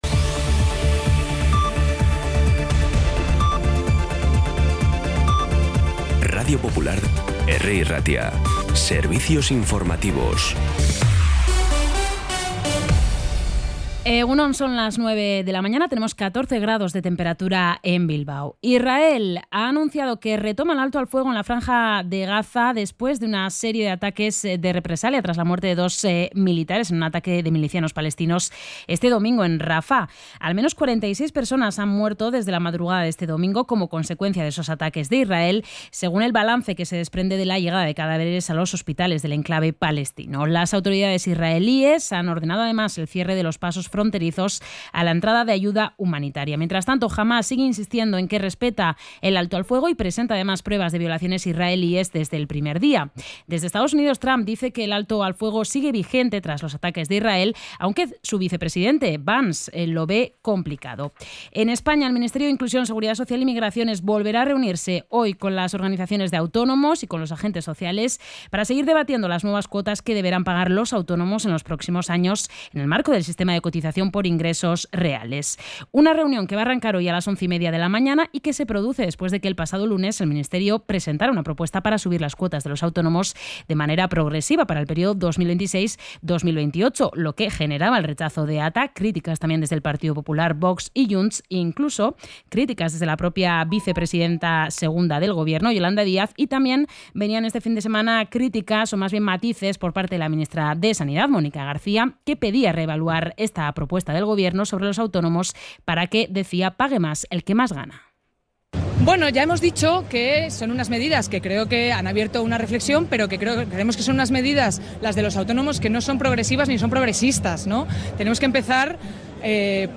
Las noticias de Bilbao y Bizkaia de las 9 , hoy 20 de octubre
Los titulares actualizados con las voces del día. Bilbao, Bizkaia, comarcas, política, sociedad, cultura, sucesos, información de servicio público.